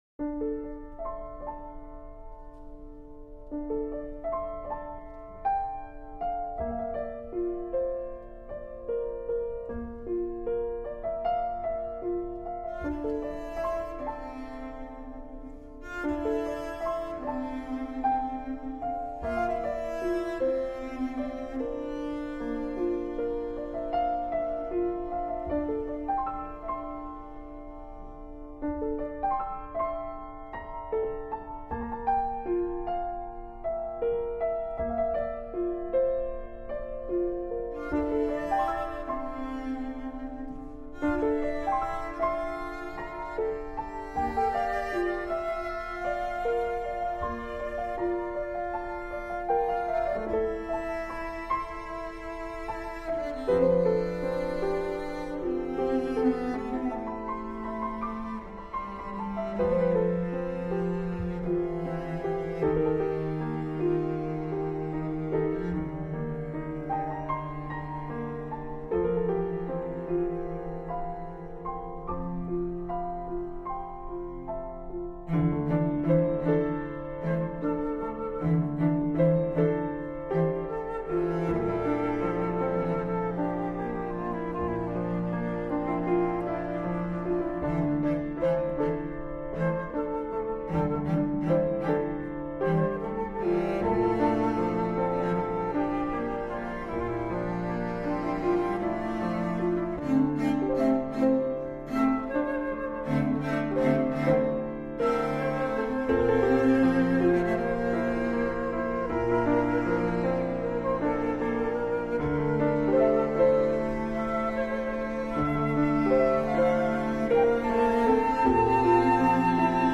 For piano, flute and cello
June 1997, Frederick Loewe Theatre, New York